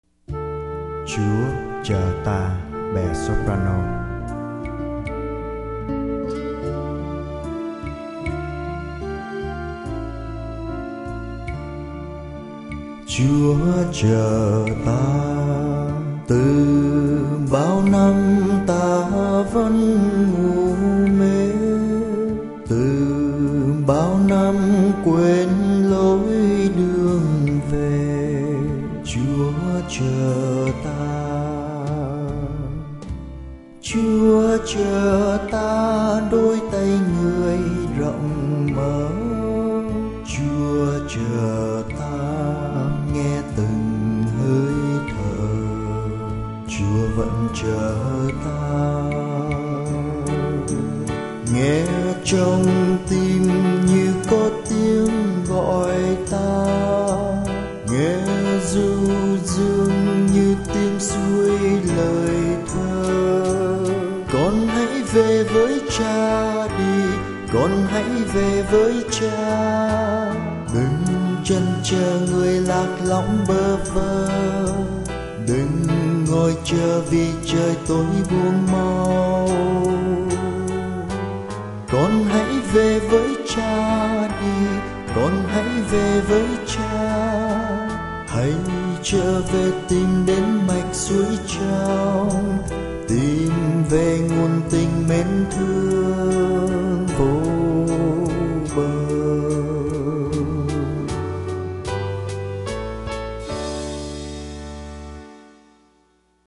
ChuaChoTa_Sop.mp3